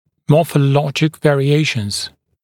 [ˌmɔːfə’lɔʤɪk ˌveərɪ’eɪʃnz][ˌмо:фэ’лоджик ˌвэари’эйшнз]морфологические варианты, морфологические вариации